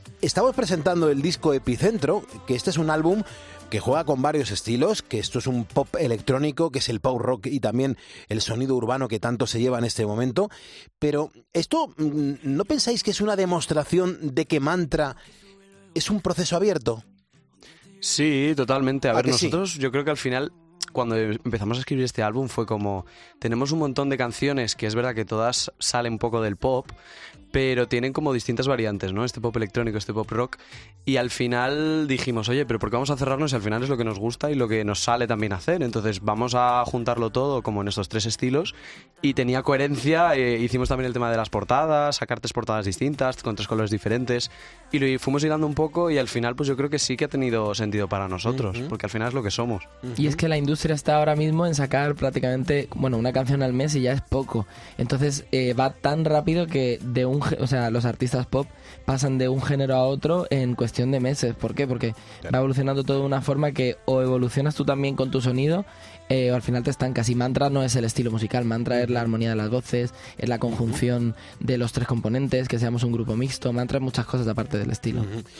El grupo musical Mantra, en 'Poniendo las Calles'